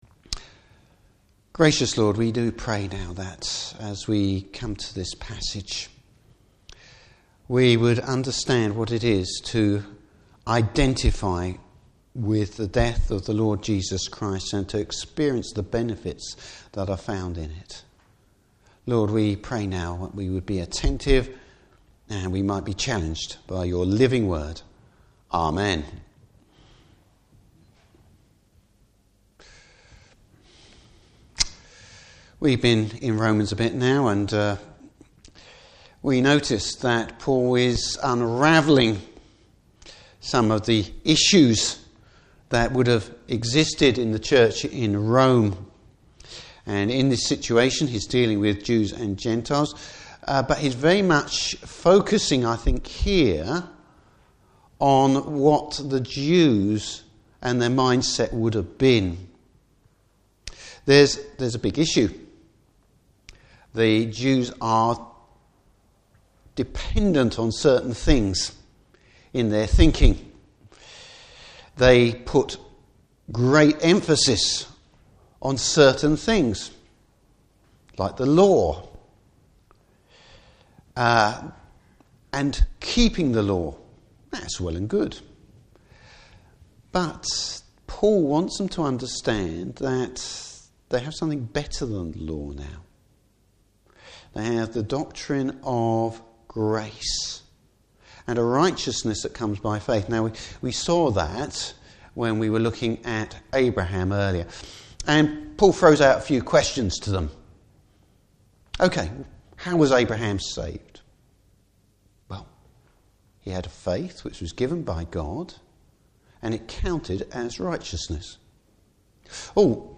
Service Type: Morning Service Free yes!